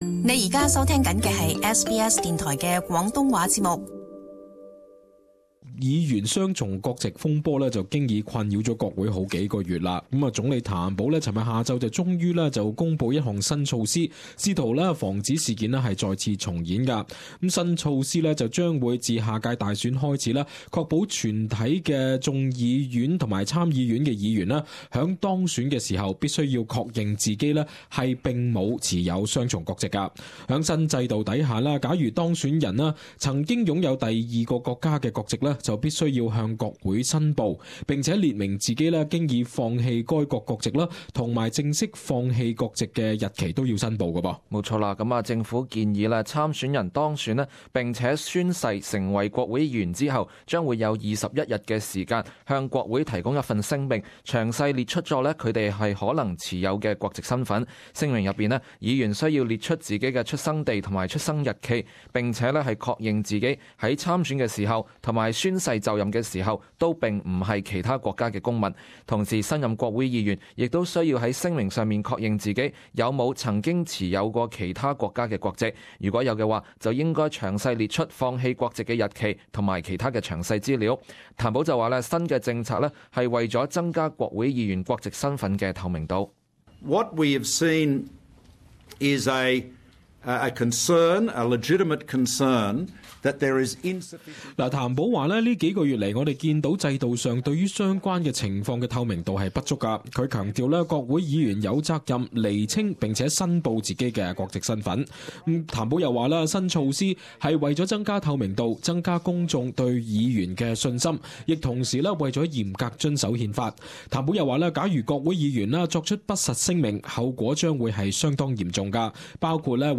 【時事報導】工黨原則上同意議員申報國籍